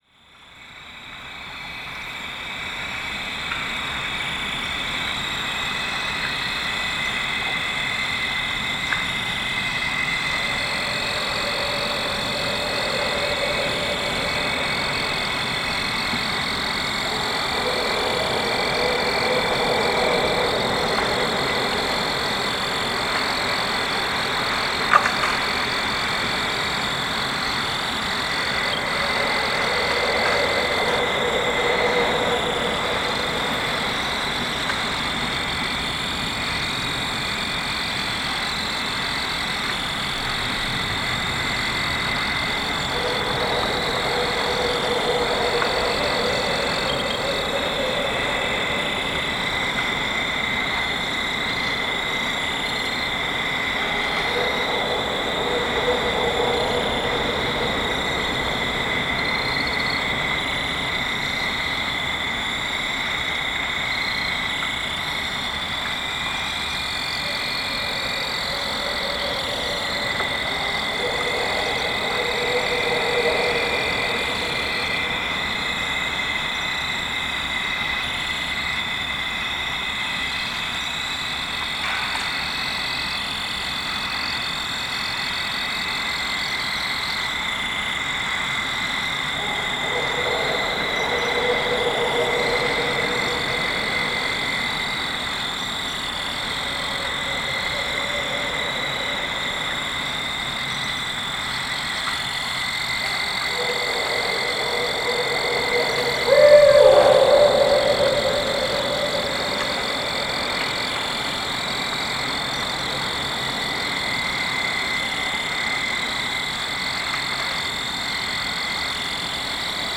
Barred owls, cow, wind, & fall field crickets (Neches riverbottom)
Category 🌿 Nature
ambiance ambience ambient Autumn barred-owl barred-owls birds cow sound effect free sound royalty free Nature